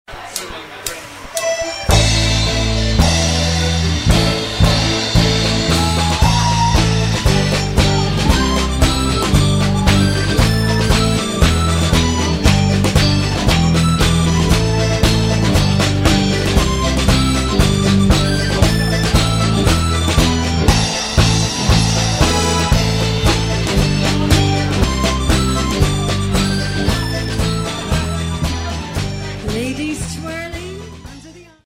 These sound clips (below) were recorded at a live Scottish Ceilidh themed event:
Please note: the band's instrumentation for the ceilidh material is: Drums, bass, guitar, keys/piano and the maximum duration of the band's ceilidh set is 45 mins.
CeilidhGayGordons.mp3